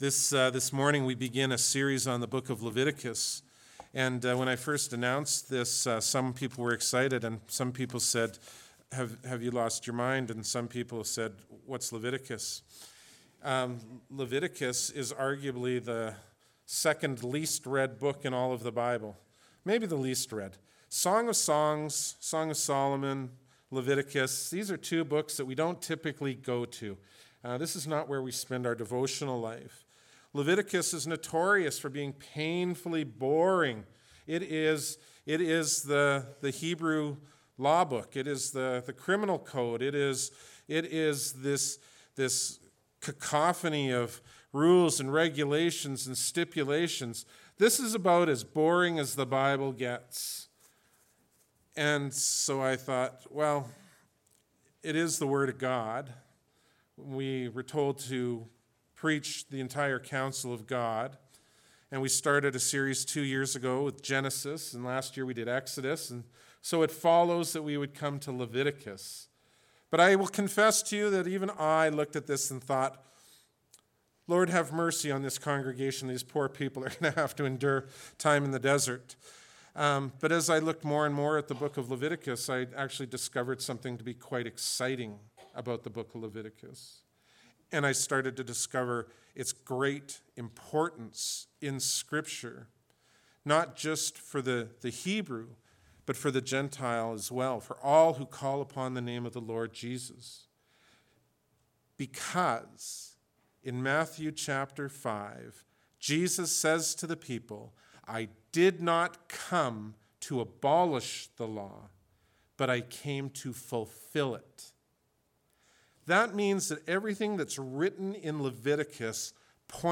Bible Text: John 11:21-27, 38-44 | Preacher